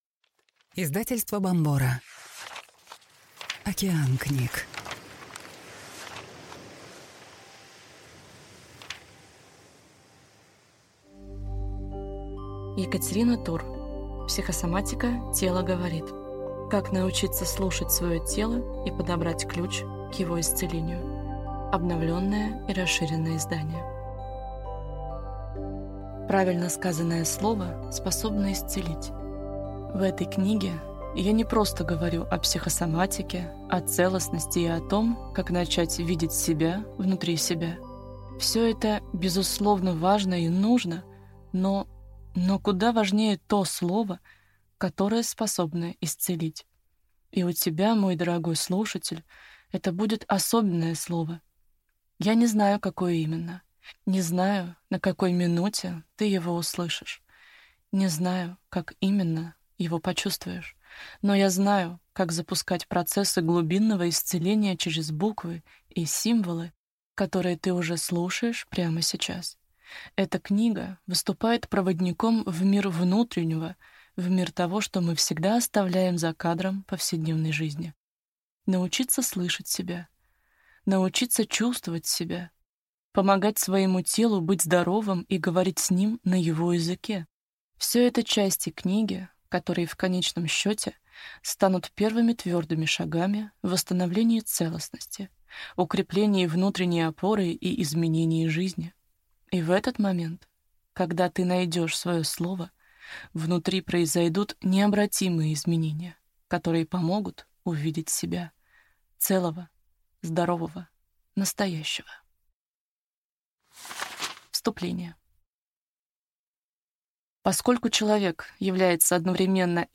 Слушать аудиокнигу Психосоматика: тело говорит.